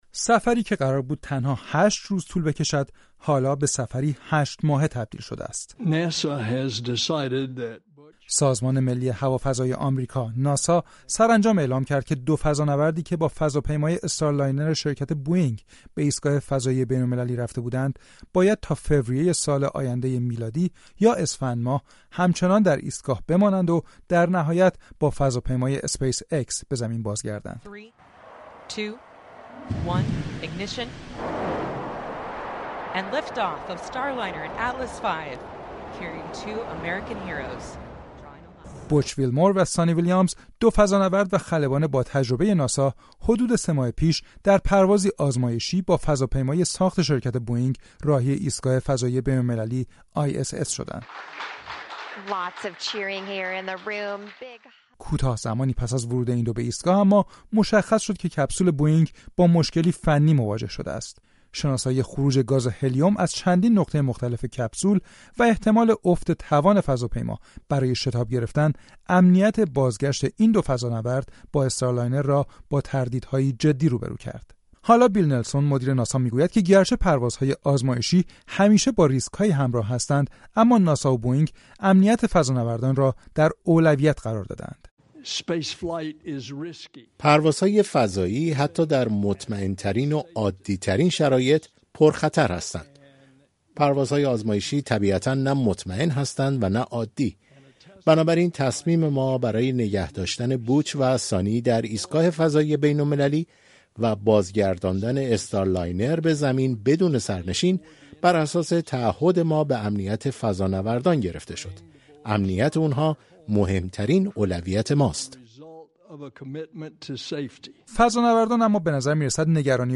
گزارش رادیویی گرفتار شدن دو فضانورد ناسا به‌دلیل نقص فنی استارلاینر